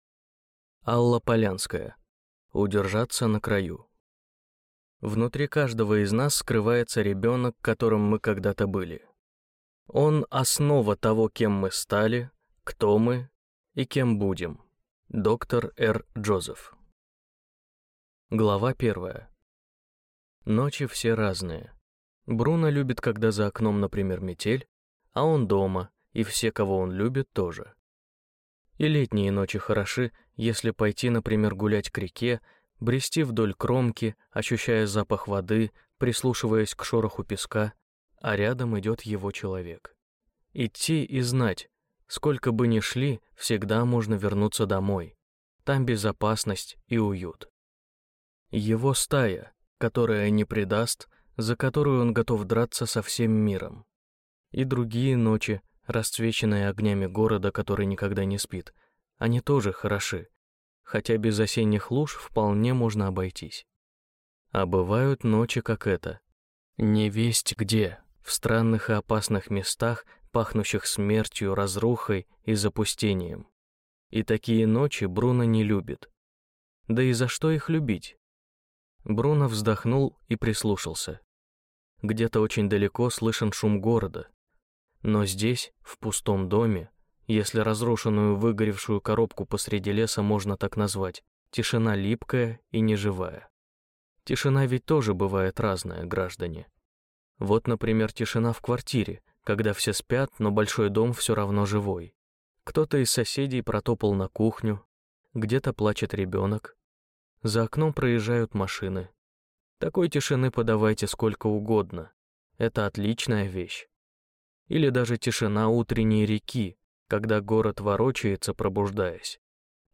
Аудиокнига Удержаться на краю | Библиотека аудиокниг
Прослушать и бесплатно скачать фрагмент аудиокниги